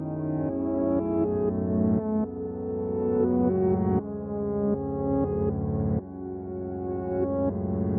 反转的陷阱钢琴旋律
Tag: 120 bpm Trap Loops Piano Loops 1.35 MB wav Key : Unknown